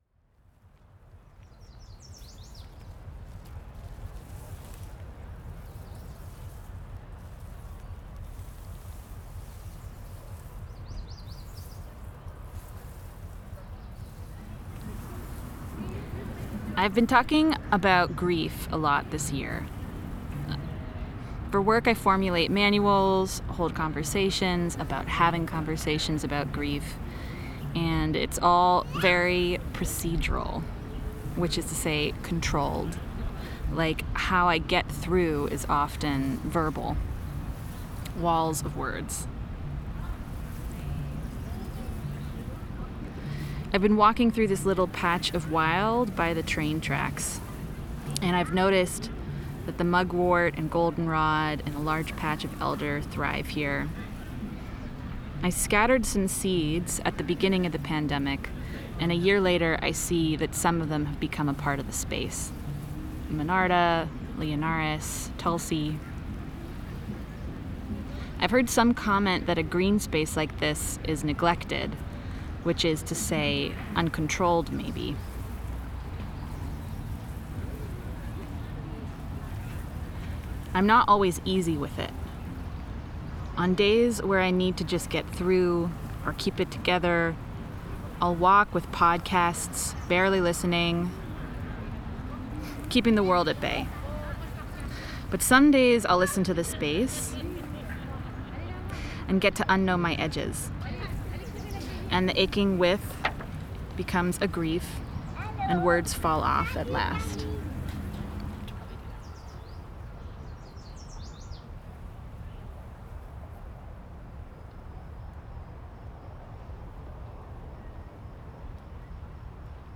Cette pièce est une réflexion sur le processus d’accueil du deuil discuté par Haraway, un processus souvent bloqué par la croyance que seul le langage porte le poids du processus de deuil. Enregistrement effectué à la passerelle des Champs des possibles.